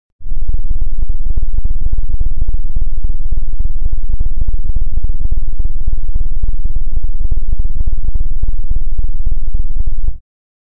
На этой странице собраны записи инфразвука — низкочастотных колебаний, находящихся за пределами обычного человеческого восприятия.
Инфразвук на частоте 7 Гц